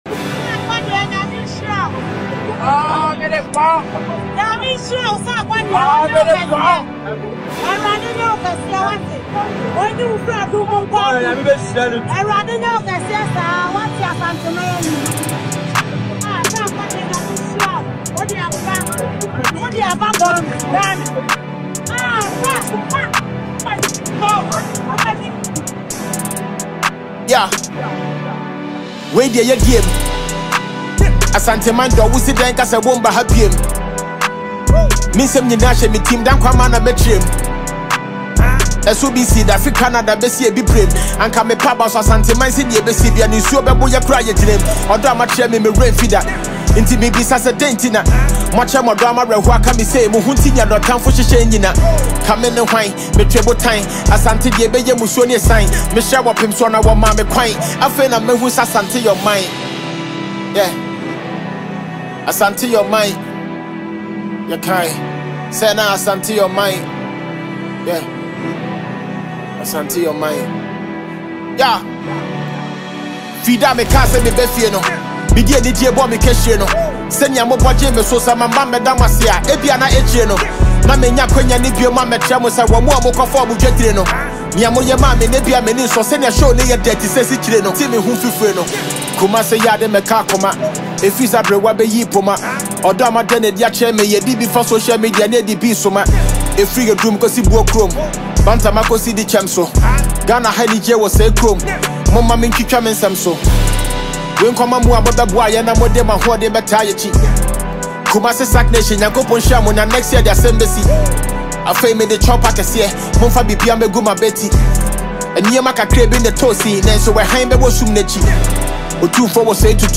Ghanaian rap heavyweight